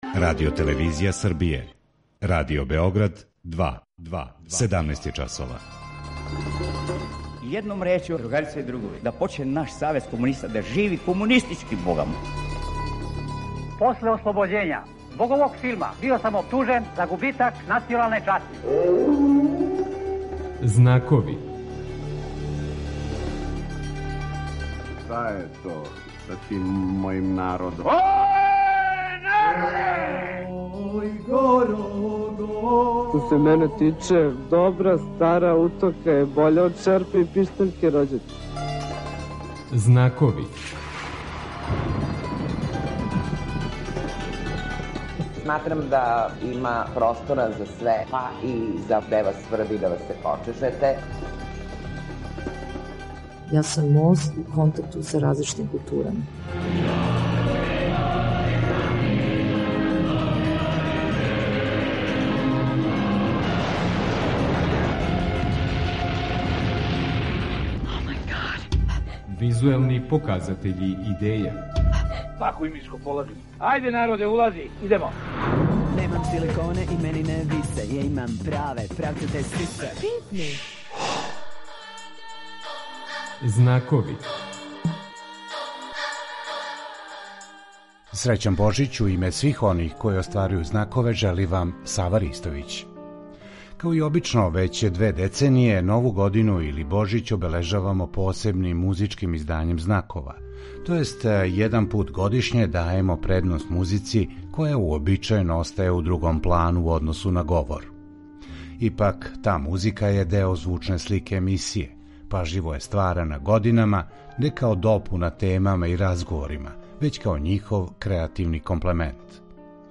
Као и обично, већ 2 деценије, Нову годину или Божић Знакови обележавају специјалним, музичким издањем.
Данас, међутим, изостаће и тема и прича и биће само музика, и ништа више.